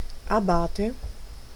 Ääntäminen
Ääntäminen RP : IPA : /ˈæb.ət/ GenAm: IPA : /ˈæb.ət/ US : IPA : [ˈæ.bət] Lyhenteet ja supistumat Ab abb.